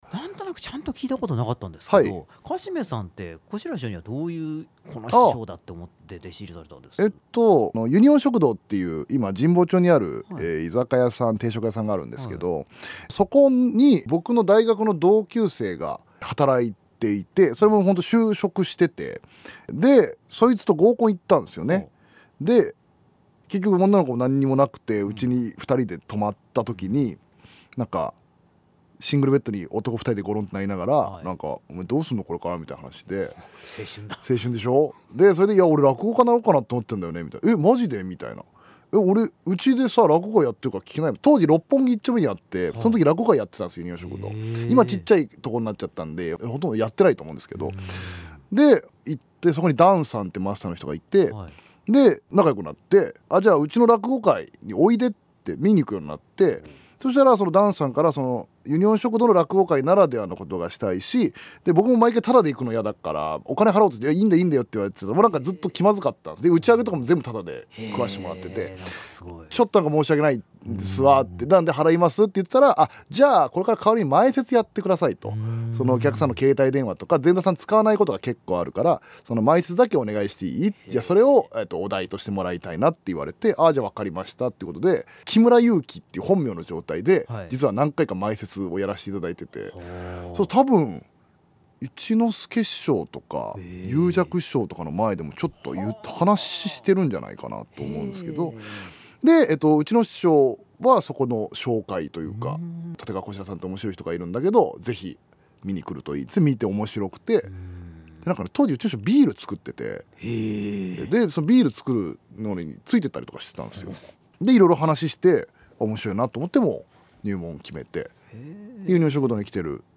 本編放送の前に、放送時間の関係で泣く泣くカットしたＯＰトーク(約6分弱)をこちらで公開いたします。